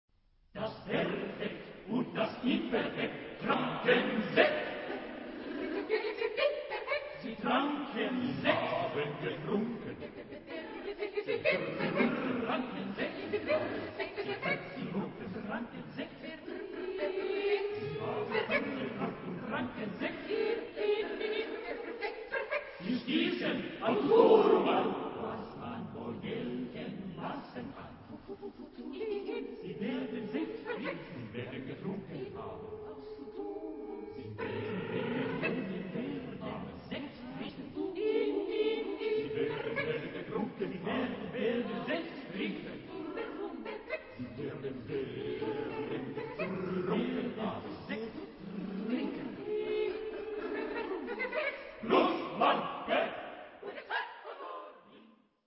SATB (4 gemischter Chor Stimmen).
SATB (4-stimmiger gemischter Chor )